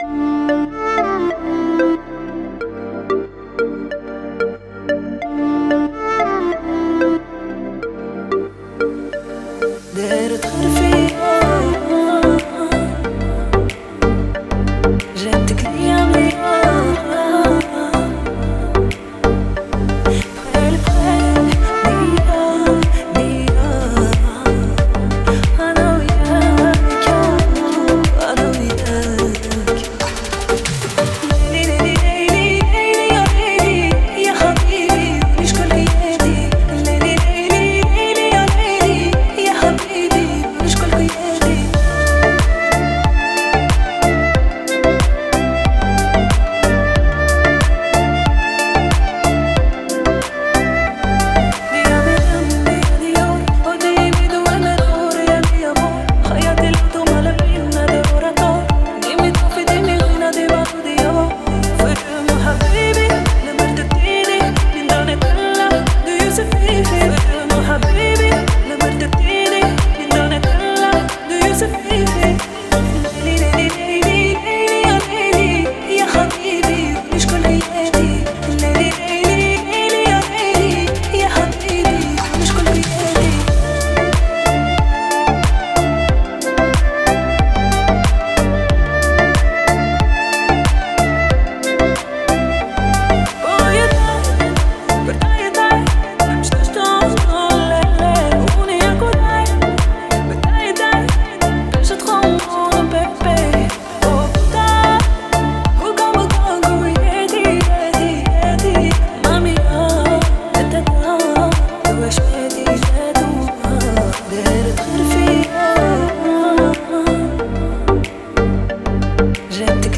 Трек размещён в разделе Узбекская музыка.